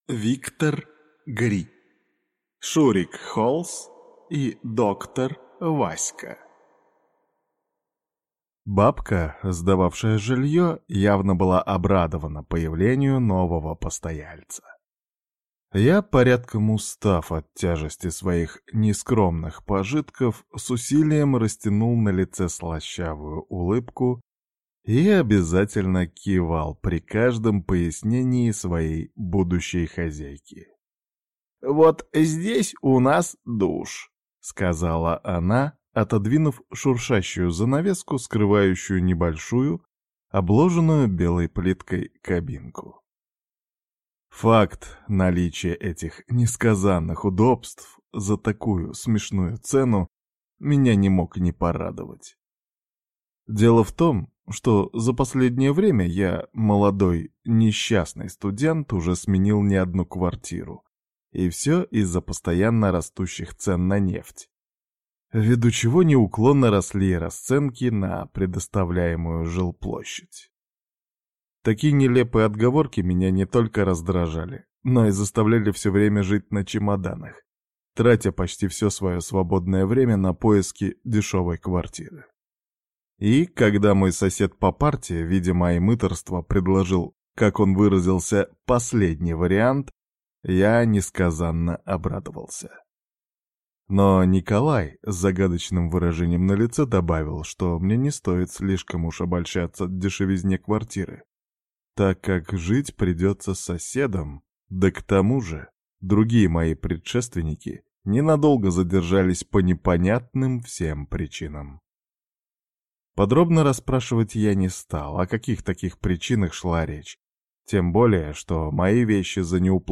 Аудиокнига Шурик Холс и Доктор Васька | Библиотека аудиокниг
Прослушать и бесплатно скачать фрагмент аудиокниги